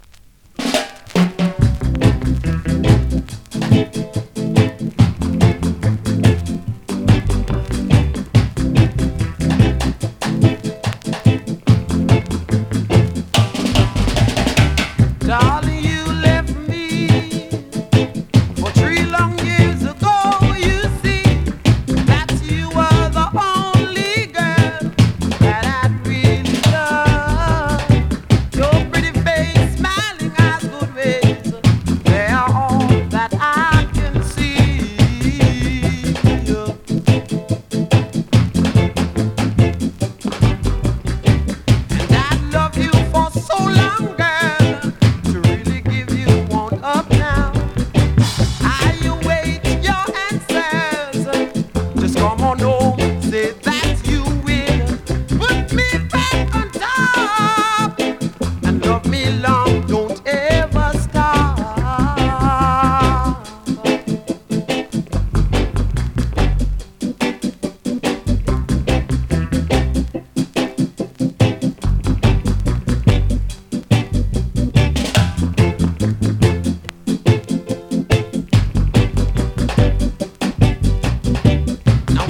NEW IN!SKA〜REGGAE
スリキズ、ノイズ比較的少なめで